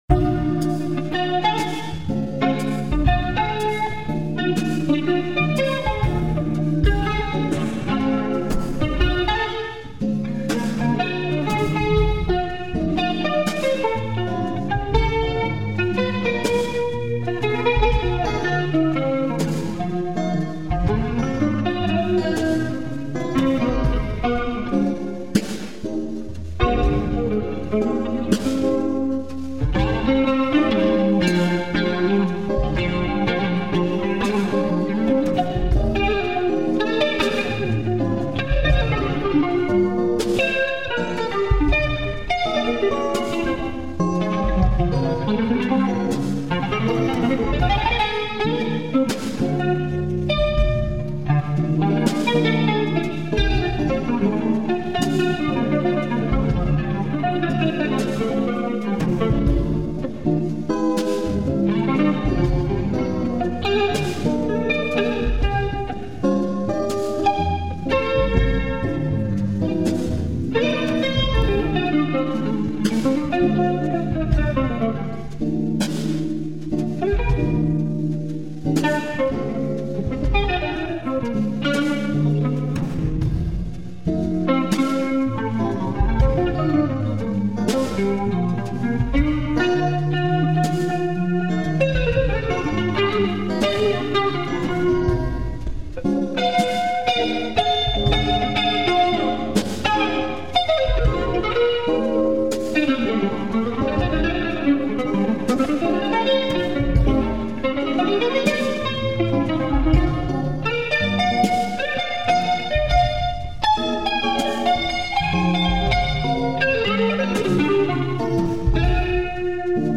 Un mia idea in 7/4
blues-f-01.mp3